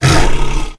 c_sibtiger_hit1.wav